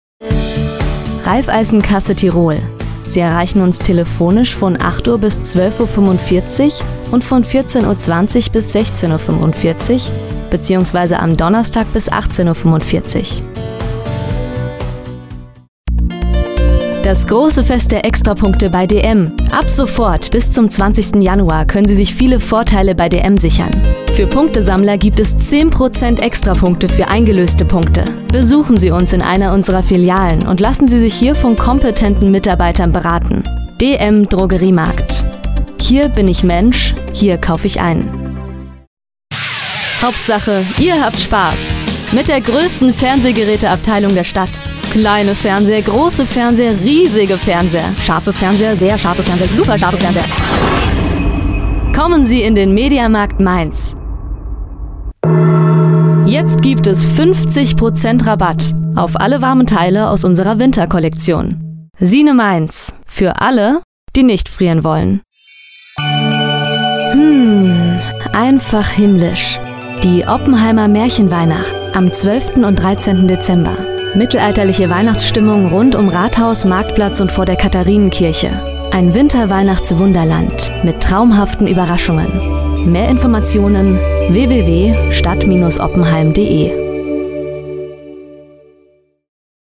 deutsche Sprecherin
mittel tiefe Stimme, warme Klangfarbe
Sprechprobe: Werbung (Muttersprache):